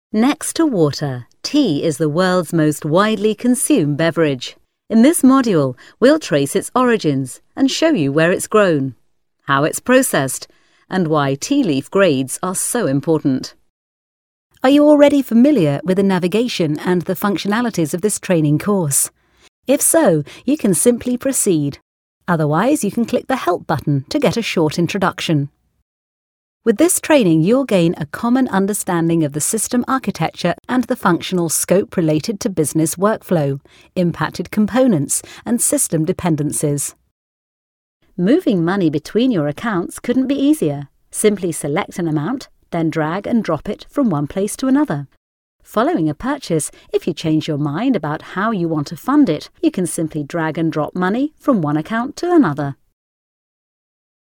Englisch (UK)
Weiblich